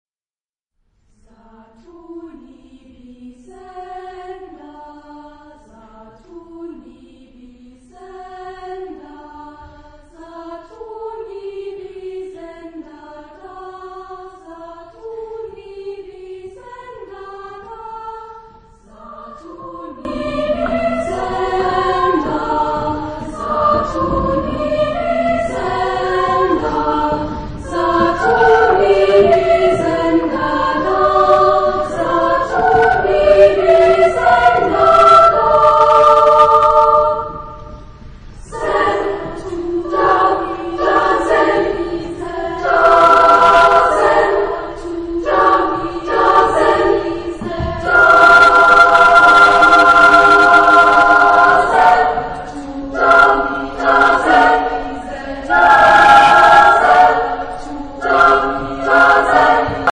Text in: onomatopoeia
Genre-Style-Form: Vocal piece ; Secular
Type of Choir: SSAAAA  (6 women voices )
Tonality: free tonality
: 7. Deutscher Chorwettbewerb 2006 Kiel